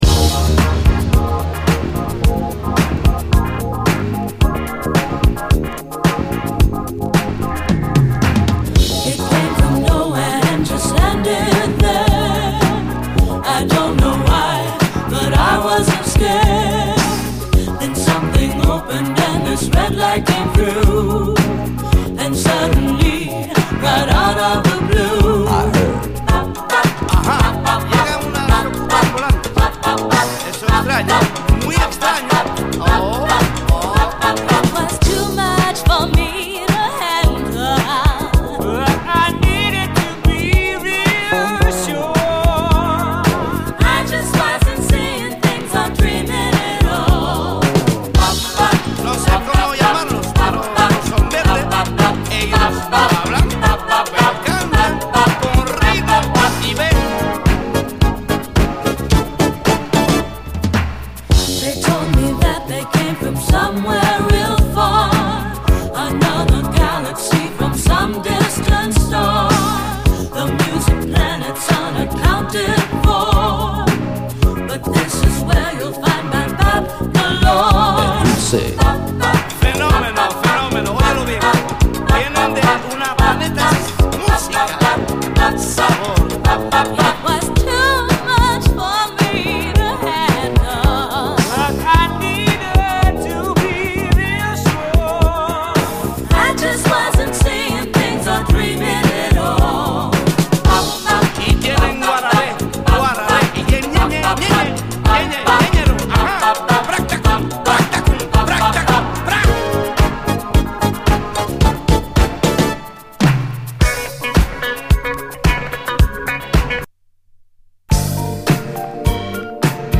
SOUL, 70's～ SOUL, DISCO
両面洒落てるフランス産ユーロ・ディスコ〜メロウ・シンセ・ソウル！
フランス録音、フランス・オリジナル盤！